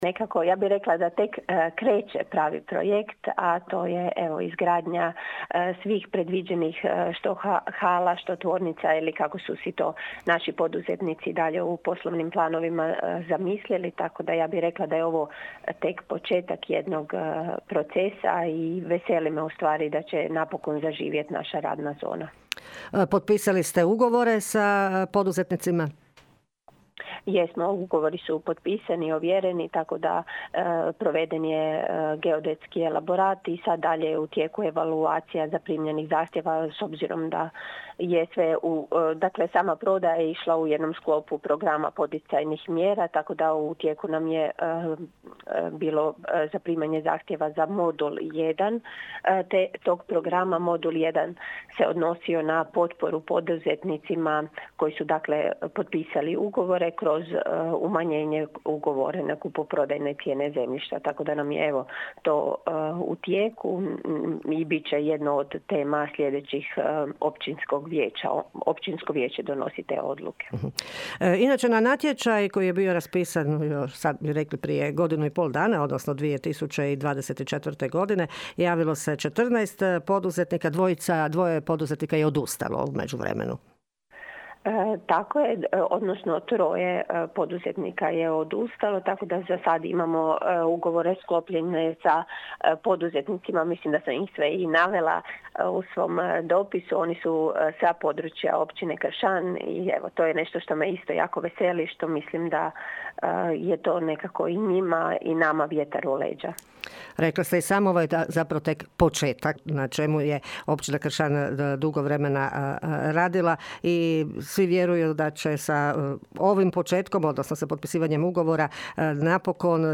Govori načelnica Općine Kršan Ana Vuksan: (